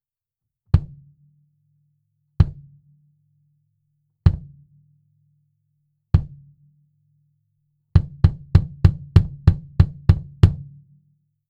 実際の録り音
バスドラム IN
kick-in2.wav